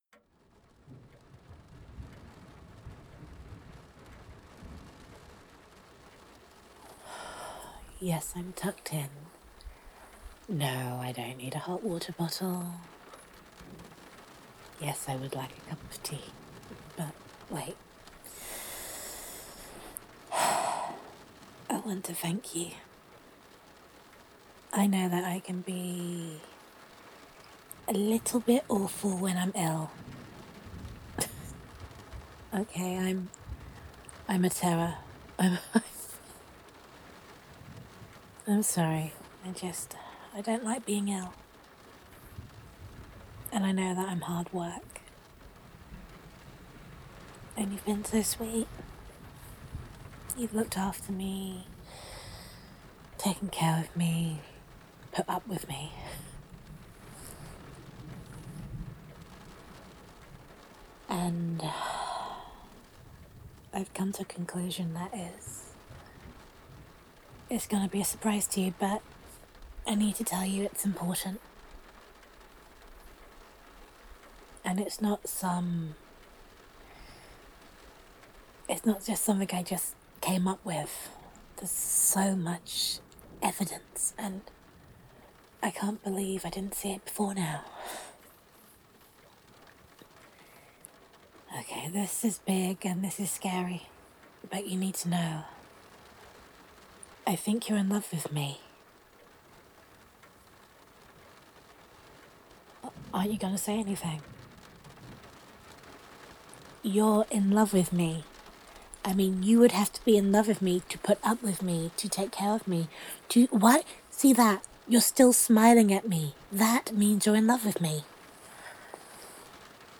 Character Type: Sickly yet Love-struck Individual
Emotional Tone or Mood: Warm, Introspective, Slightly Humorous Content Type: Audio Roleplay
[Rainy Ambience]